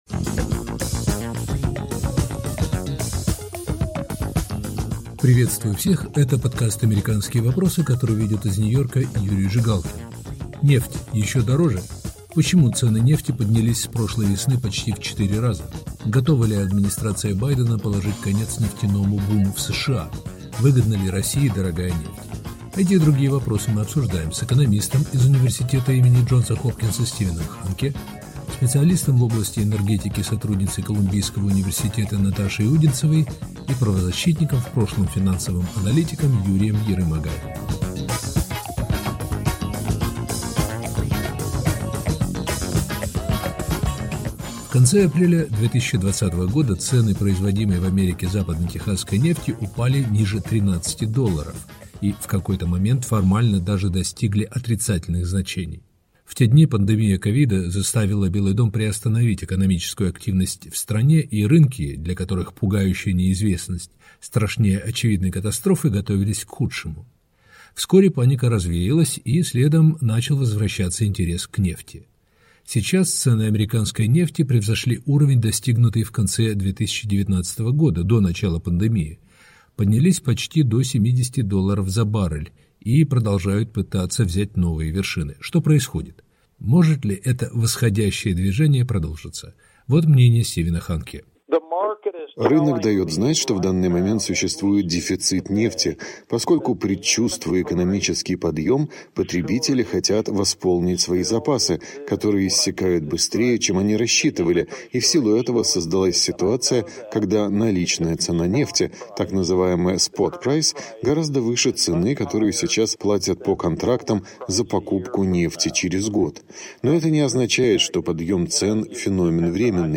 Почему цены нефти поднялись почти в четыре раза? Готова ли администрация Байдена положить конец нефтяному буму в США? Выгодна ли России дорогая нефть? Эти вопросы обсуждаем с экономистом Стивеном Ханке